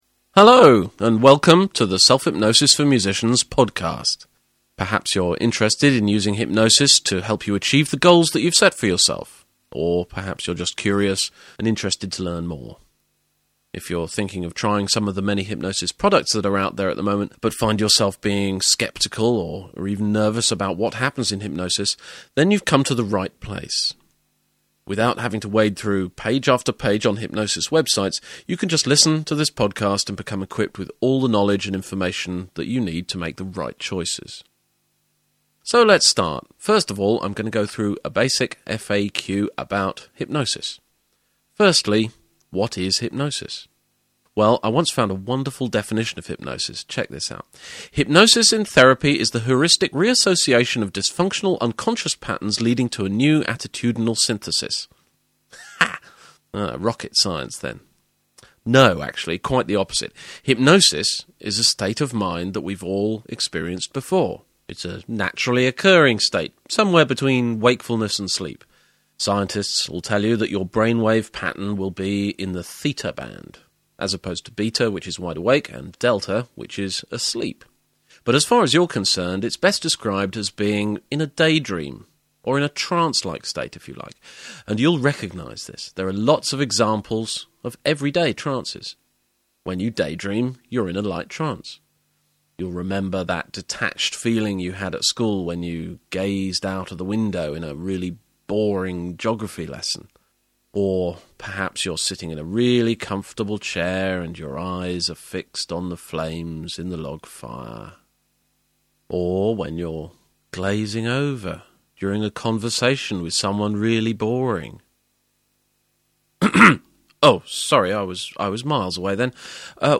Your FREE audio lecture plus more fascinating information about how this Hypnosis stuff works! Free Audio Lecture.